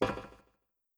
snd_footstep1.wav